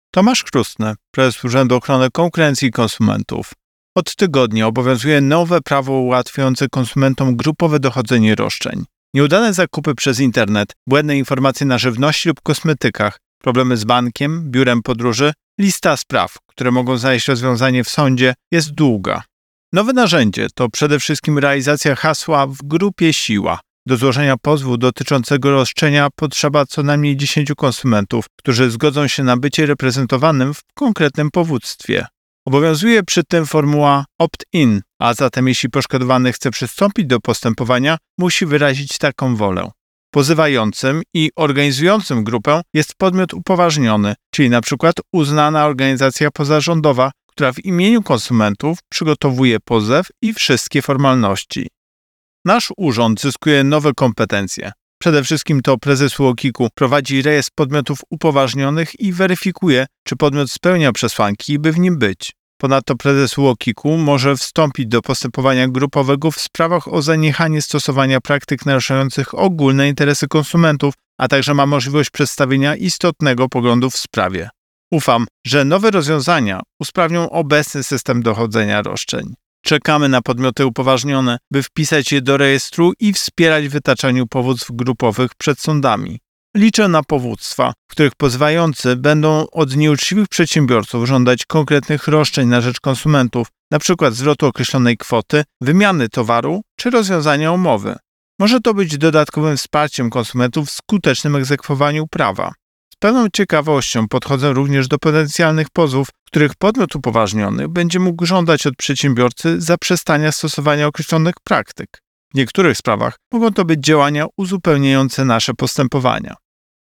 Wypowiedź Prezesa UOKiK Tomasza Chróstnego z 2 września 2024 r..mp3